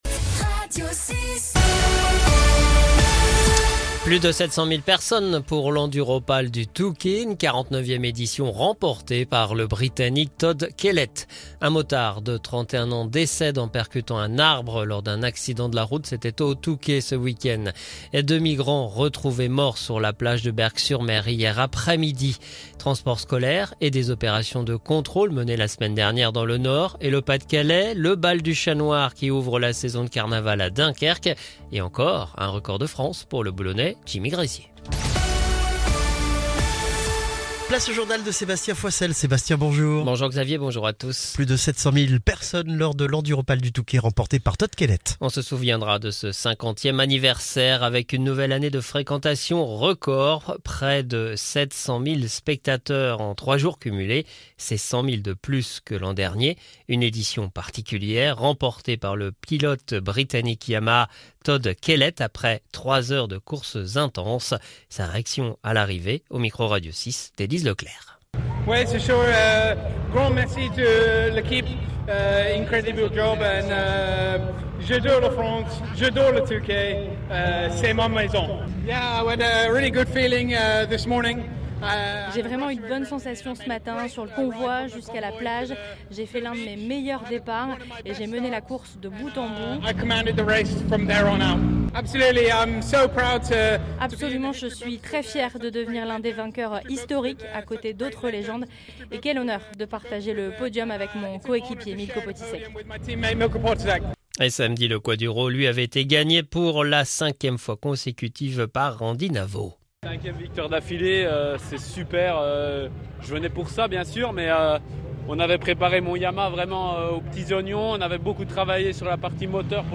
Le journal du Lundi 10 février 2025